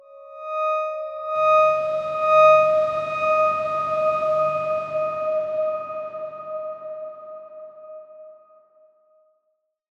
X_Darkswarm-D#5-mf.wav